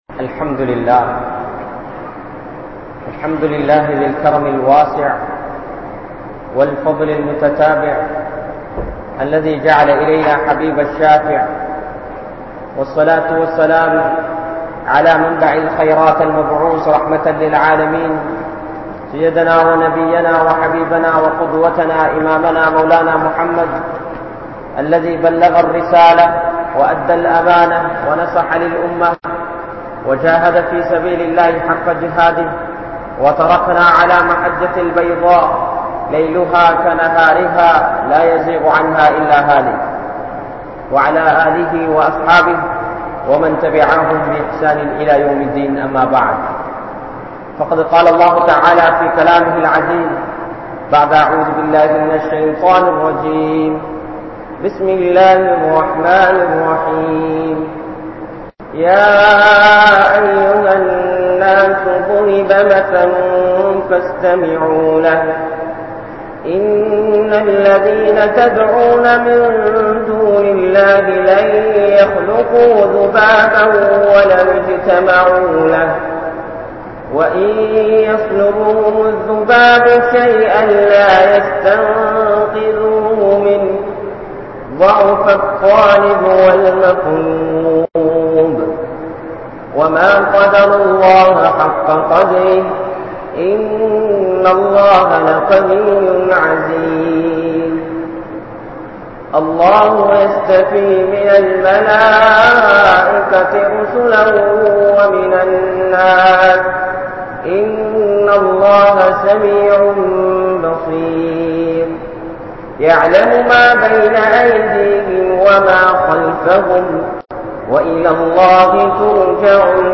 Mattravarhalin Maanaththai Parikkatheerhal (மற்றவர்களின் மானத்தை பறிக்காதீர்கள்) | Audio Bayans | All Ceylon Muslim Youth Community | Addalaichenai
Grand Jumua Masjith